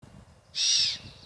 Здесь вы можете слушать и скачивать их естественные голоса: от блеяния молодых особей до предупредительных сигналов взрослых.
Звук кричащей серны